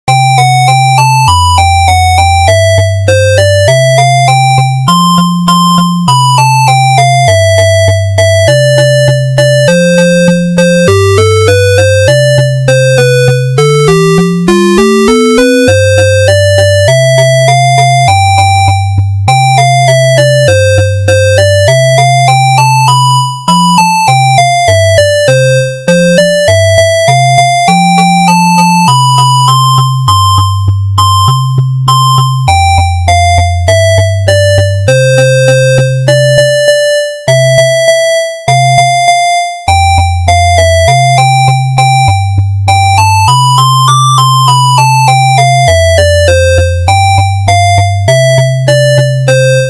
レトロ風。
BPM100
長調